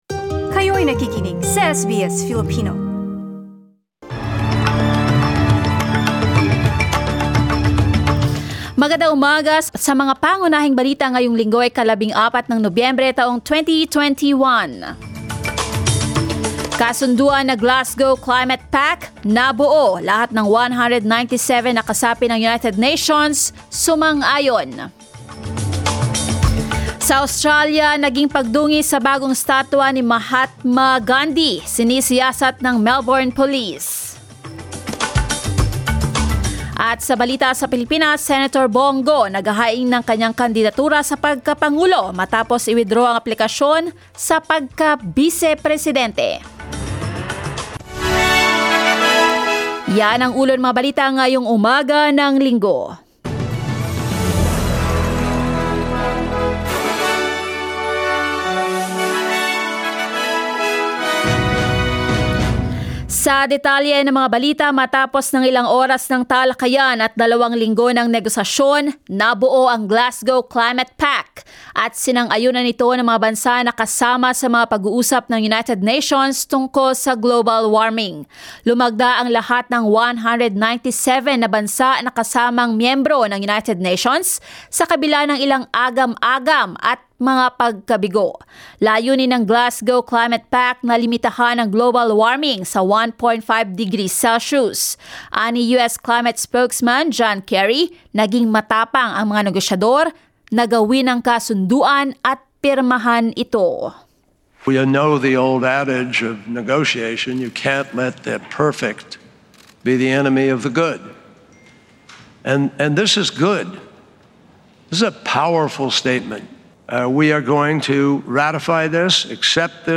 SBS News in Filipino, Sunday 14 November